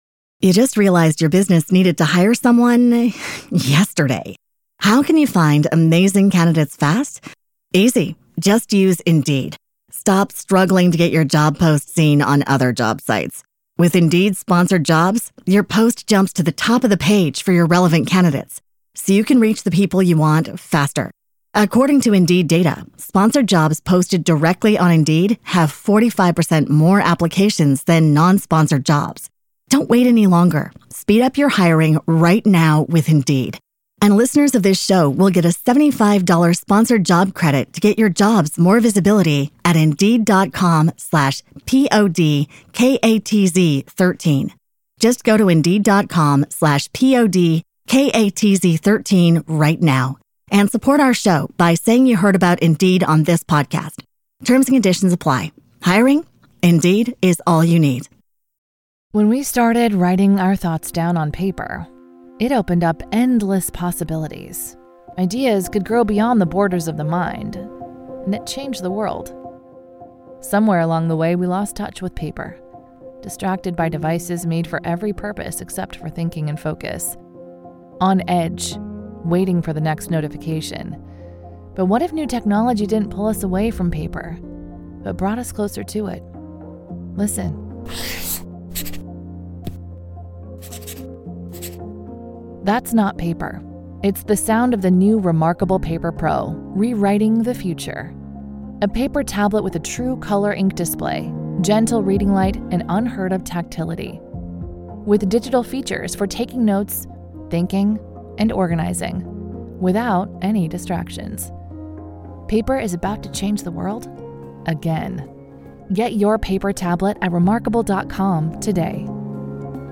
Mastering the Hustler's Mentality - Powerful Motivational Speech